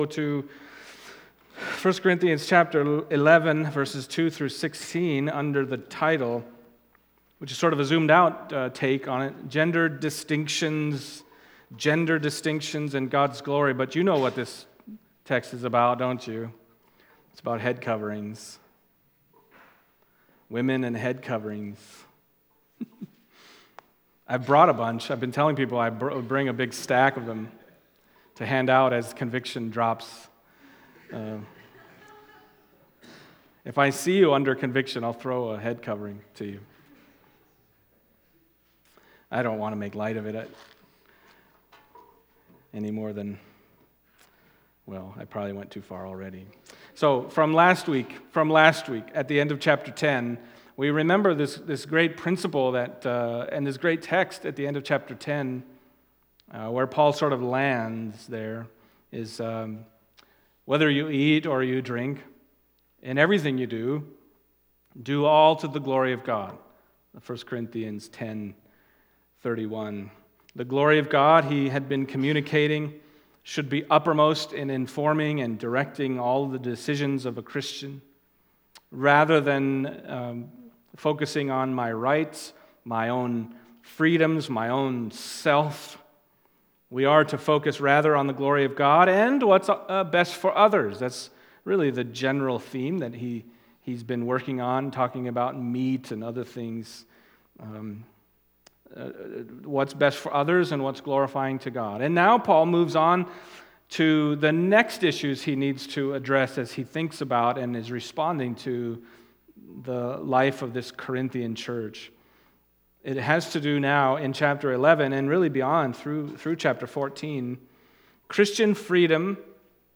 Passage: 1 Corinthians 11:2-16 Service Type: Sunday Morning